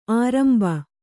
♪ āramba